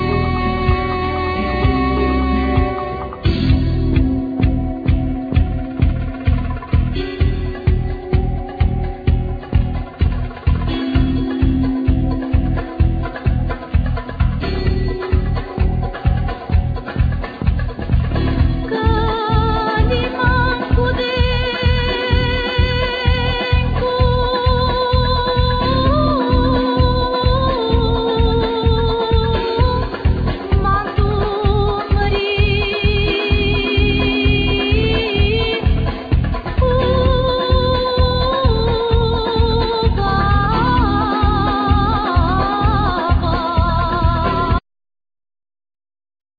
Guitar,Guitar synth
Bass
Percussion
Soprano saxphone,Flute
Lyre